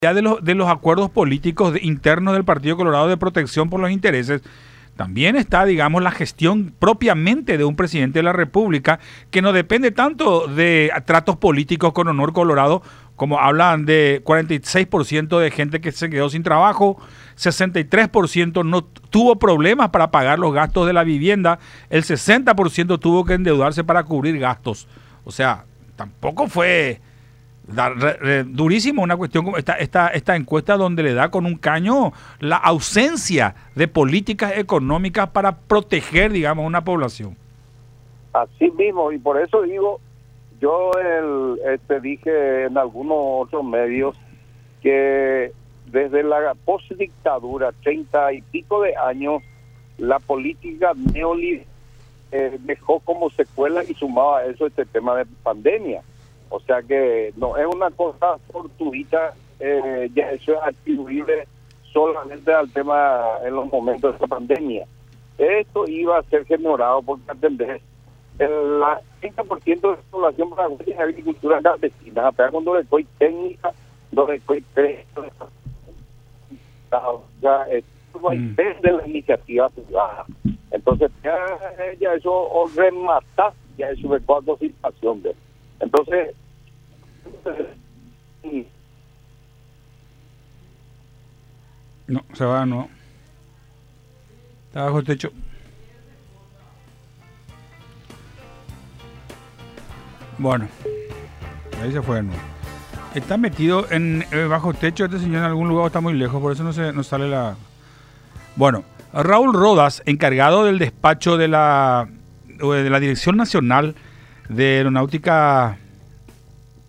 Sixto Pereira, senador del Frente Guasu.
Ahora se suma a toda la crisis económica, principalmente por el combustible”, dijo Pereira en contacto con Buenas Tardes La Unión, haciendo referencia a los porcentajes difundidos por la encuesta de la CELAG, que detallan que Abdo carga con una desaprobación del 83% en términos generales y niveles de reprobación aún mayores en torno a su gestión de la economía (86%) y de la lucha contra la corrupción (91%), de acuerdo a un estudio realizado en 14 de los 17 departamentos.